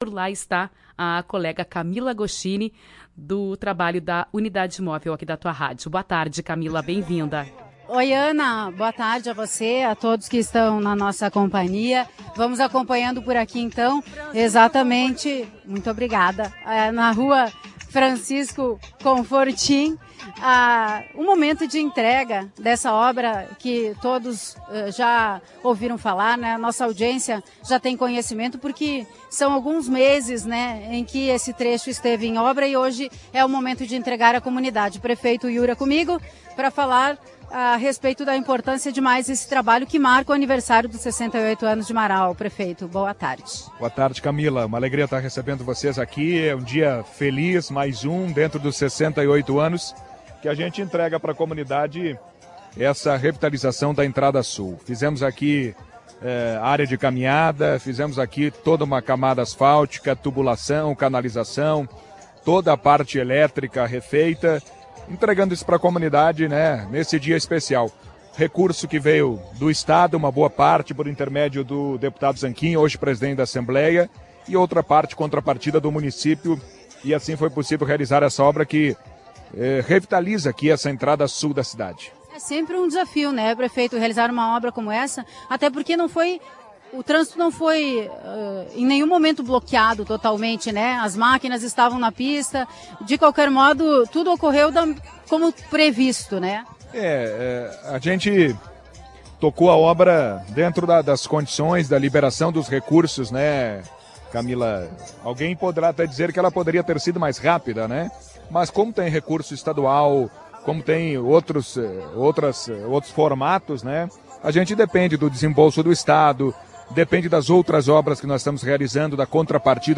Em entrevista à Tua Rádio Alvorada, o prefeito Iura Kurtz confirmou que outros trabalhos de recomposição do cenário e infraestrutura urbana são planejadas pela Administração Pública Municipal.